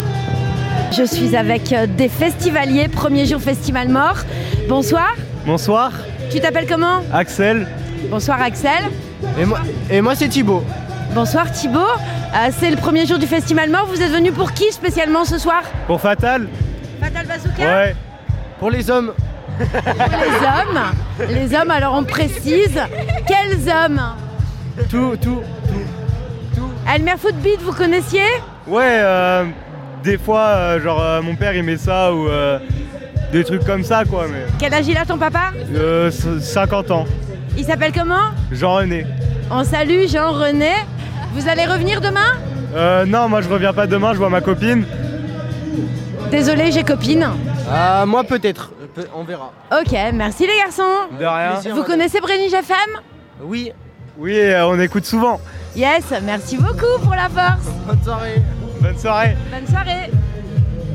Interviews Festi'malemort email Rate it 1 2 3 4 5